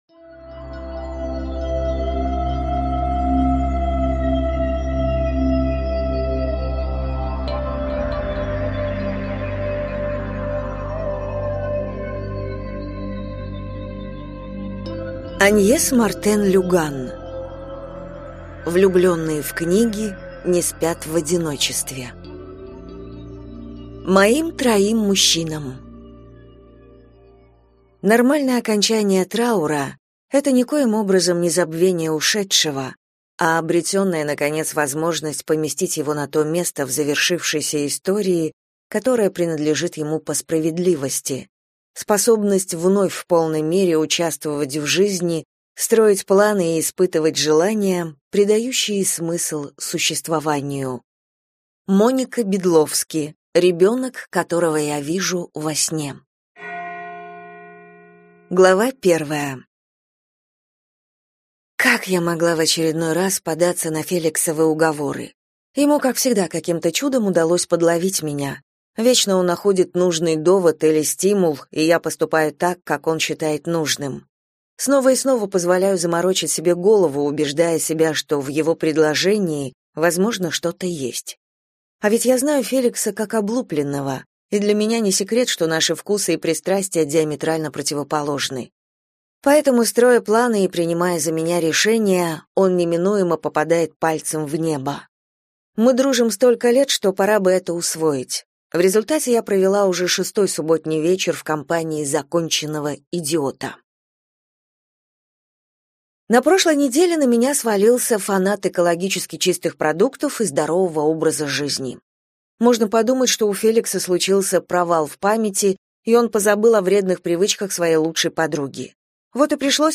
Аудиокнига Влюбленные в книги не спят в одиночестве - купить, скачать и слушать онлайн | КнигоПоиск